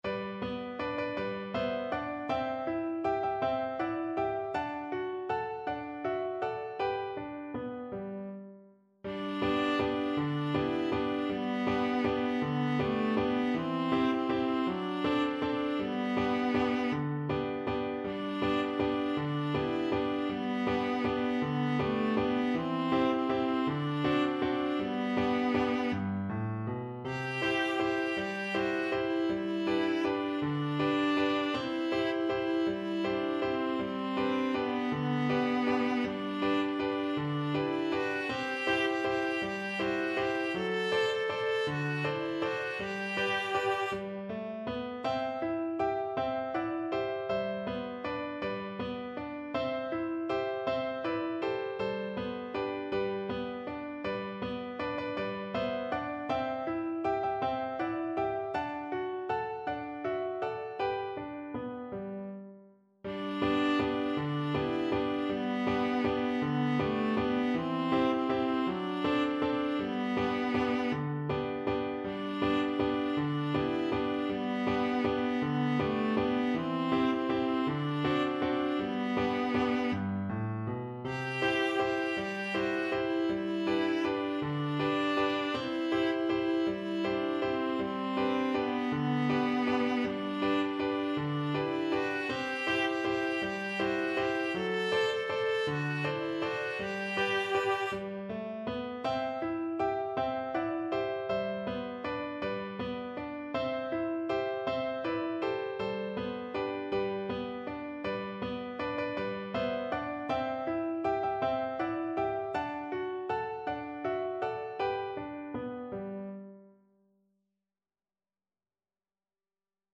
Traditional Trad. Lustig ist das Zigeunerleben Viola version
Viola
G major (Sounding Pitch) (View more G major Music for Viola )
3/4 (View more 3/4 Music)
One in a bar =c.160
Traditional (View more Traditional Viola Music)